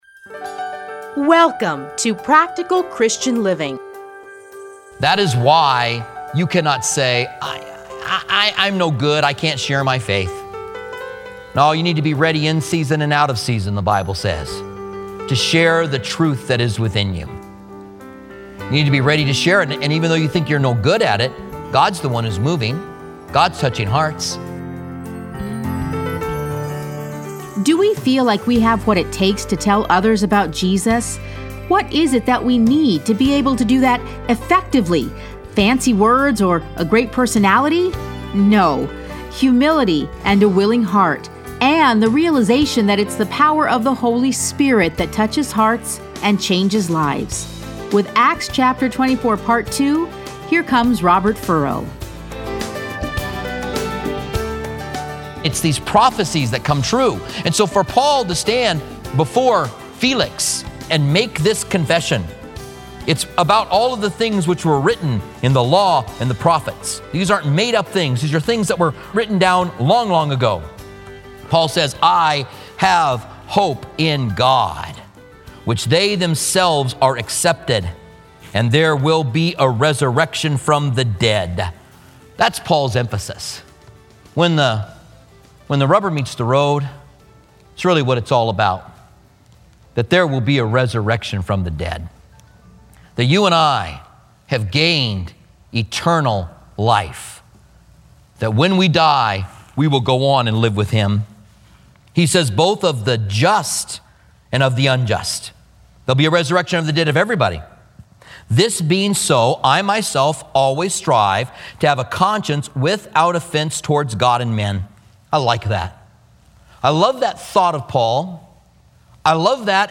Listen to a teaching from Acts 24.